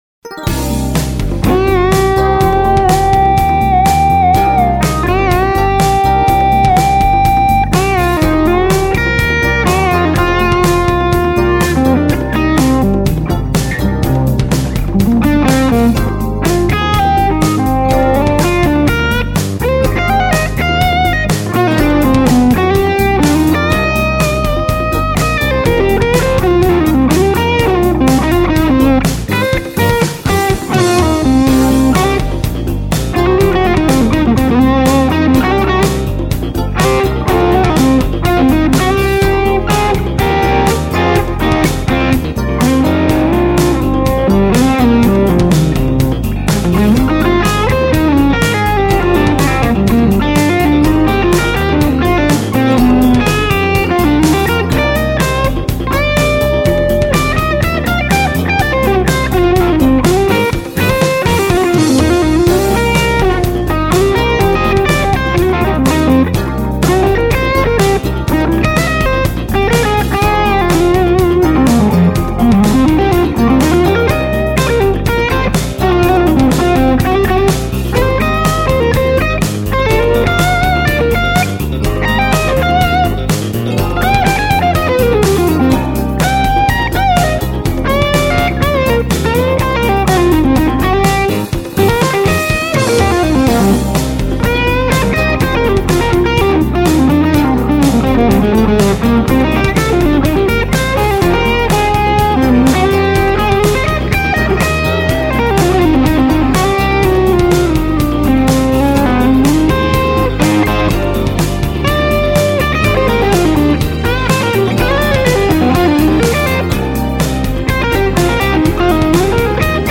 Just for giggles, I just recorded these two clips to compare the tone of Fender iron to Music Man iron.
Same mic positions.
No EQ at all. Just a touch of verb added. Same exact verb and levels between the two.
Better Bludo clip Clip three: Bludo Music Man 6L6 Skyline, me closer to cab to get more interaction